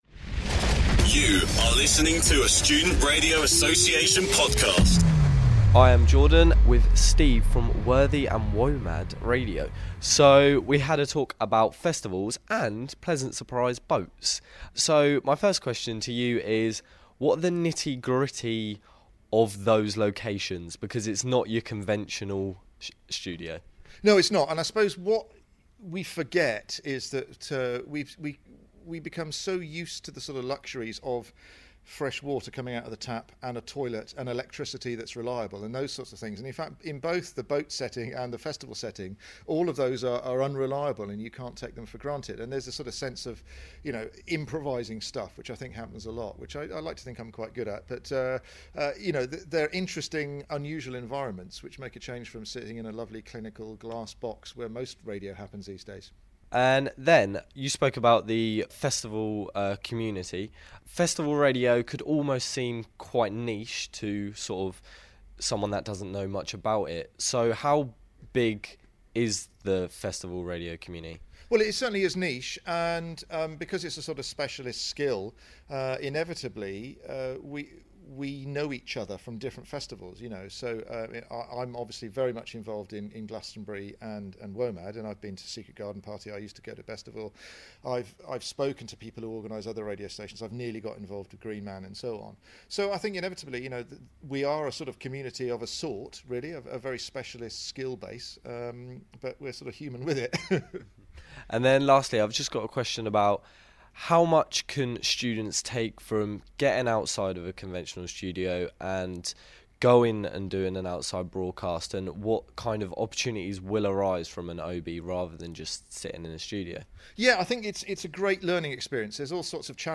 SRACon 2017: Taking Radio out of the studio is fun!